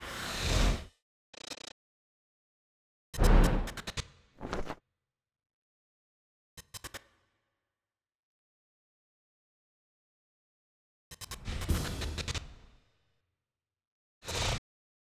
HWGA_TopEp107_15_ST_SFX.wav